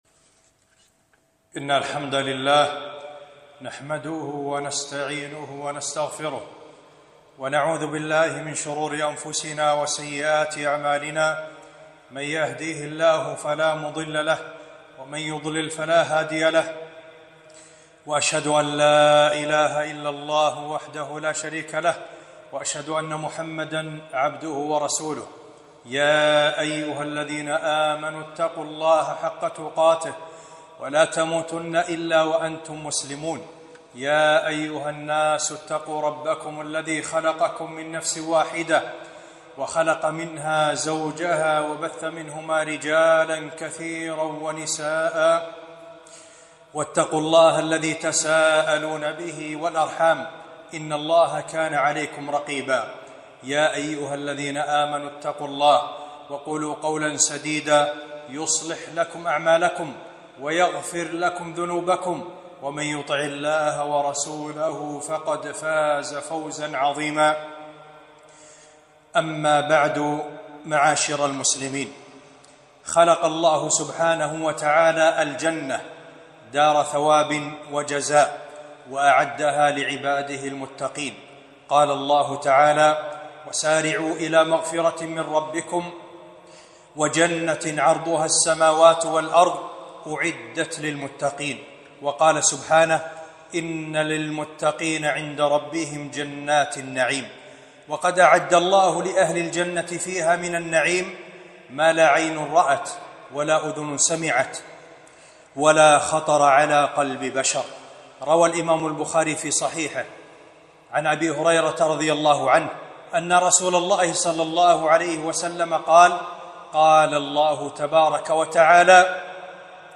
خطبة - من أسباب دخول الجنة